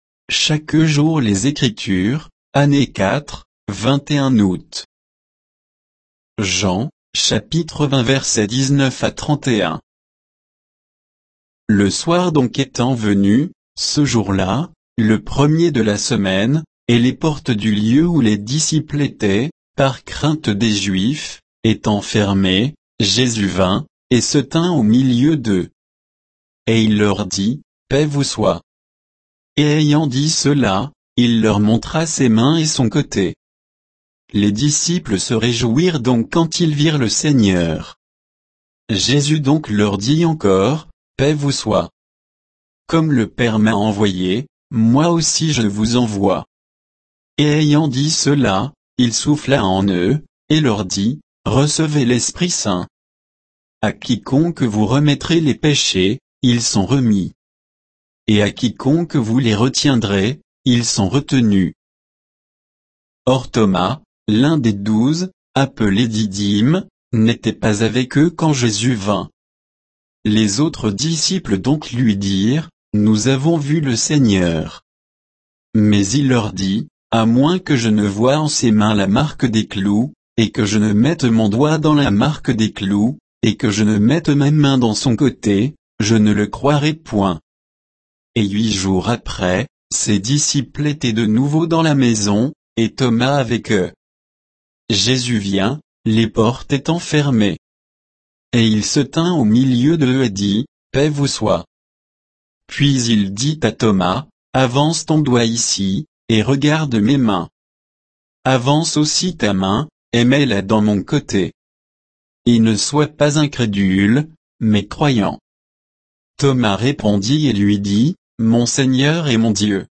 Méditation quoditienne de Chaque jour les Écritures sur Jean 20, 19 à 31